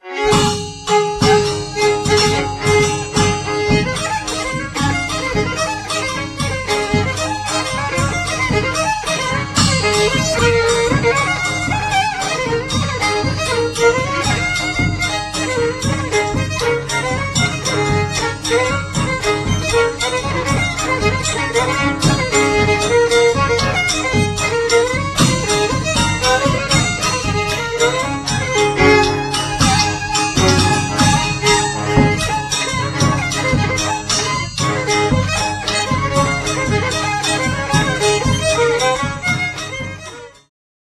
7. Oberek
skrzypce
baraban z tacą (tj. z talerzem)
basy 4-strunowe